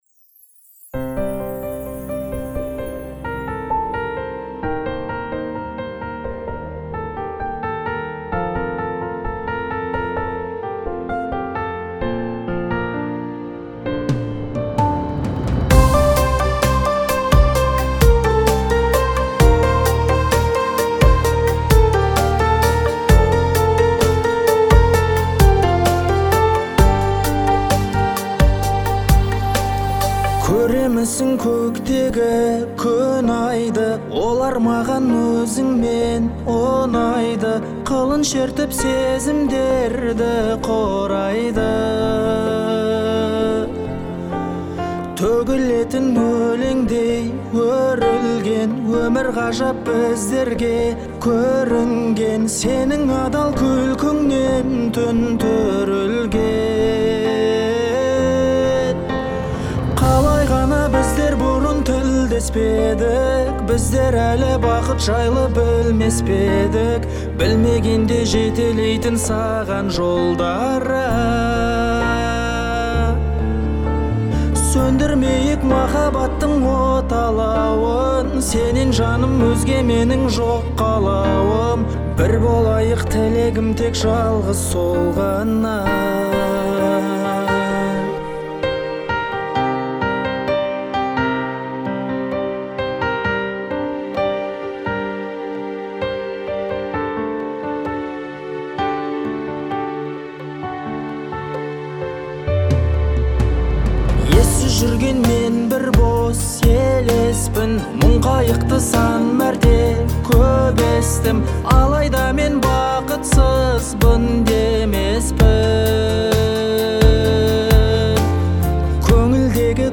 а мелодия легко запоминается